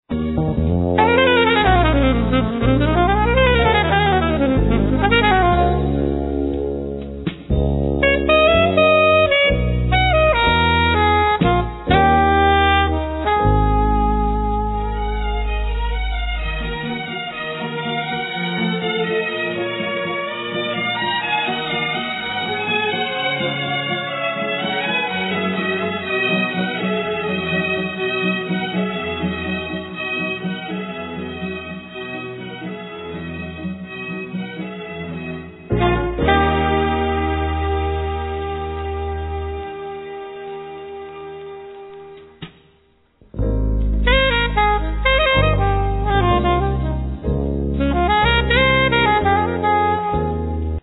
Soprano & Alto saxophone, Flute, Piano, Voice
Vibraphone, Voice, Glockenspiel
Drums, Percussions